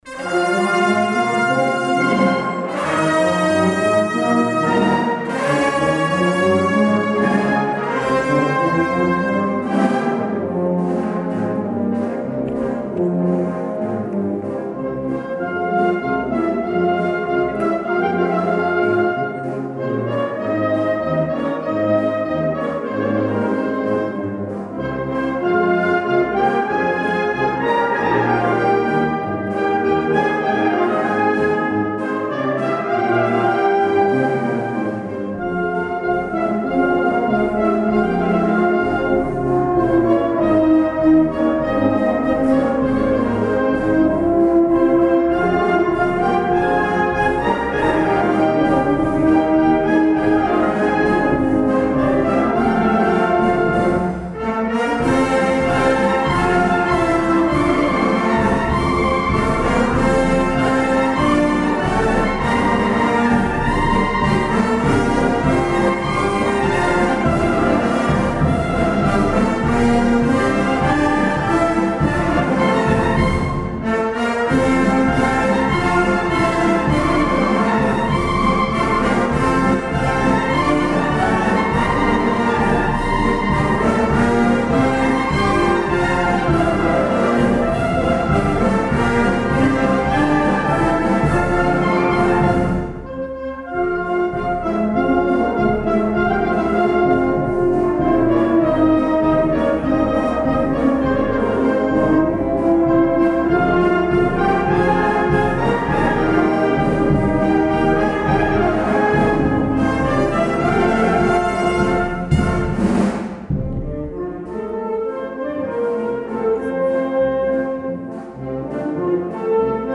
juntament amb Banda de Música de Llucmajor